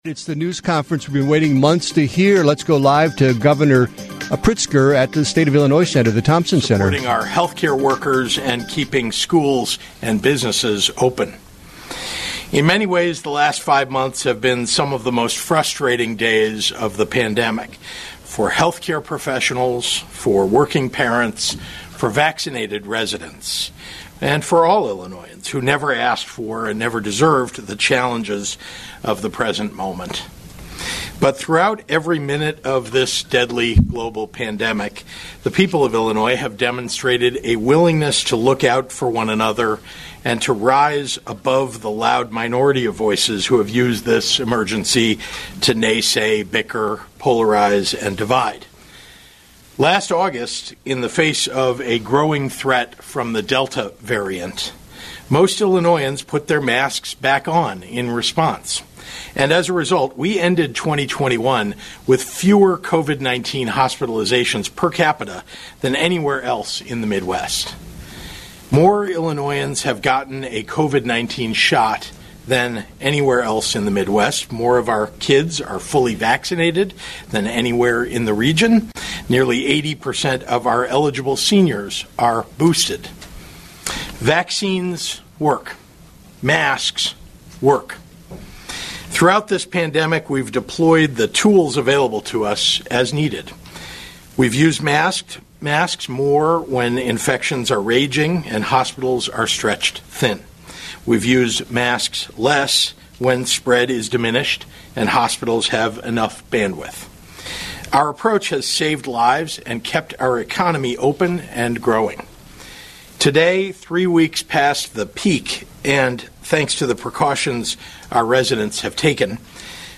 Listen to the beginning of the press conference featuring Illinois Governor J.B. Pritzker and Illinois Department of Public Health Director Dr. Ngozi Ezike: